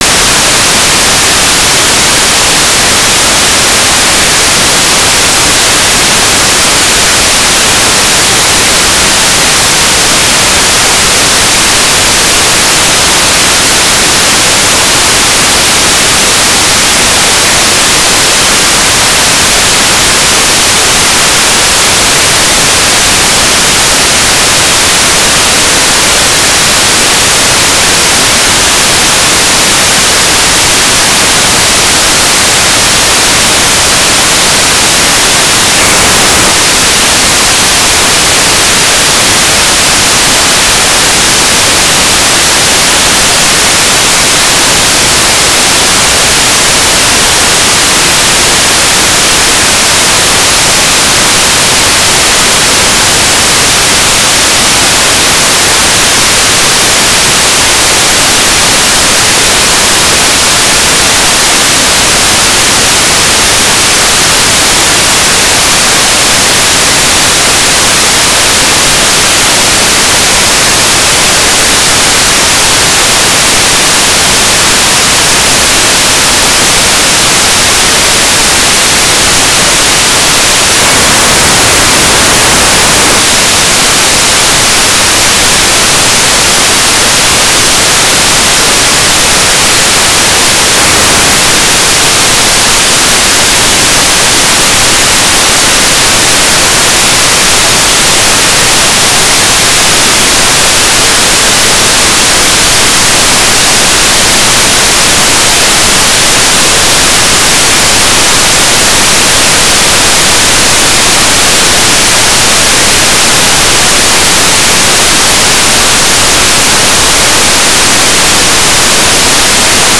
"transmitter_description": "DUV TLM",
"transmitter_mode": "DUV",